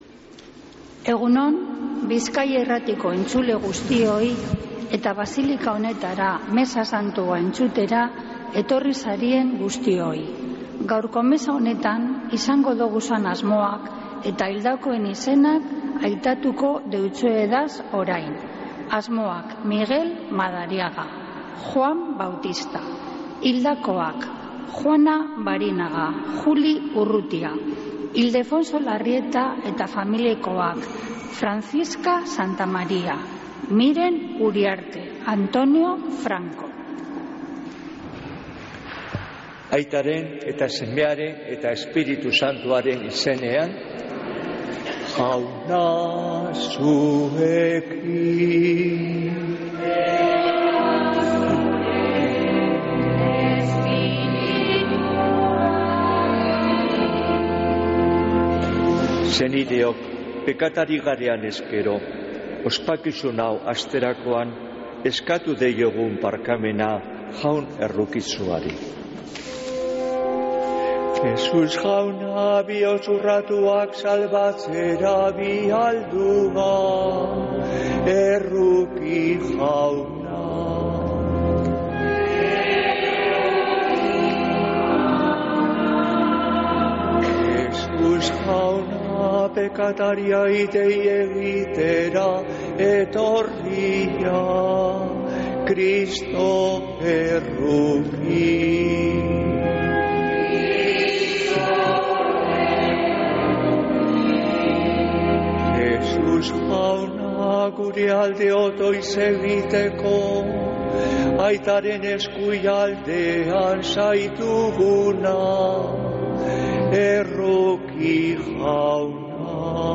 Mezea (24-12-02)